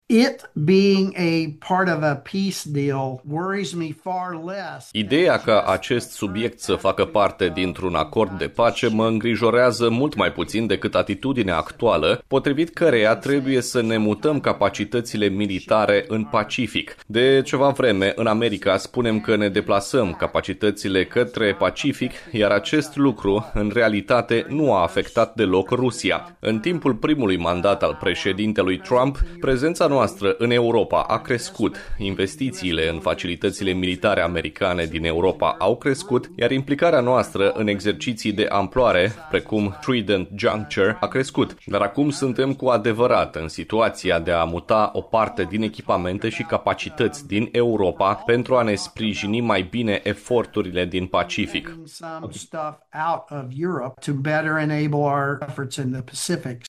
Declarațiile generalului au fost făcute într-o discuție online